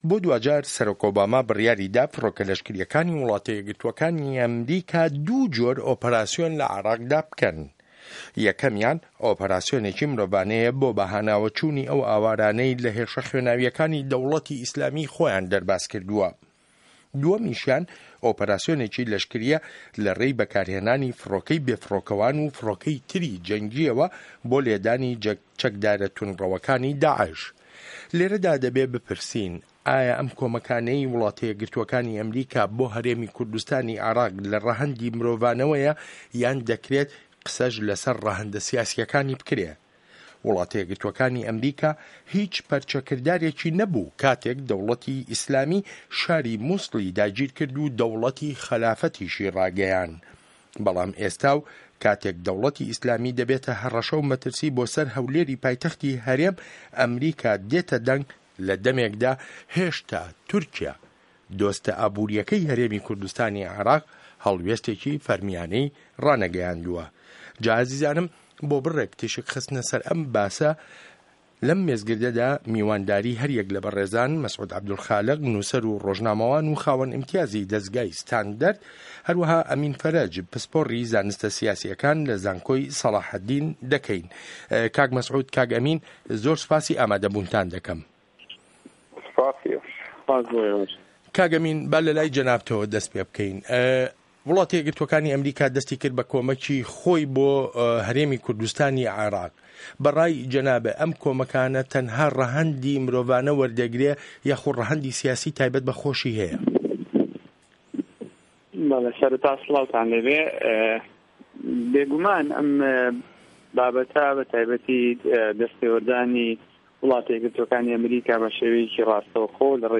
مێزگرد: ئه‌مه‌ریکا له‌ شه‌ڕی ده‌وڵه‌تی ئیسلامیدا